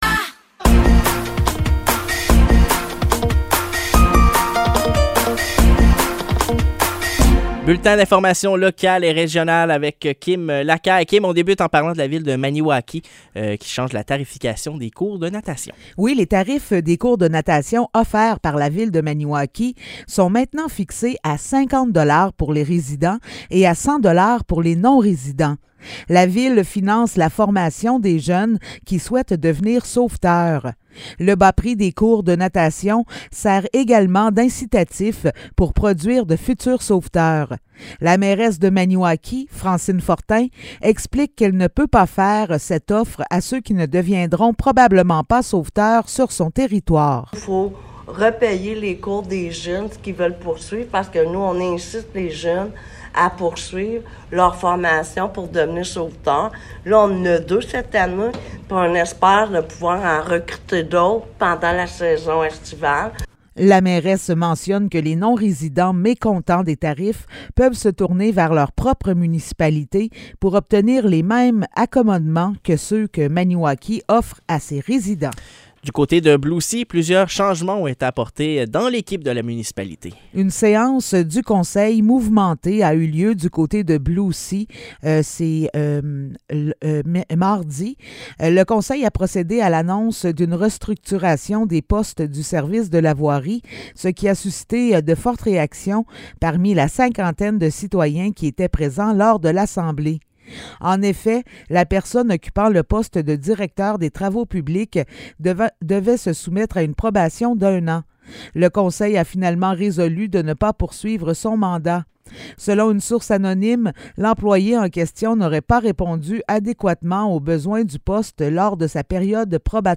Nouvelles locales - 8 juin 2023 - 7 h